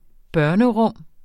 Udtale [ ˈbɶɐ̯nə- ]